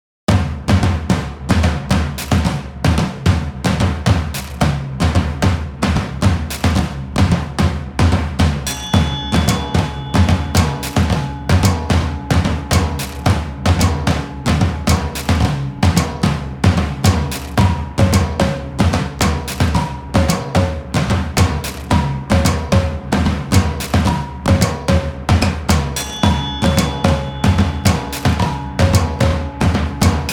une musique pop aux contours complexes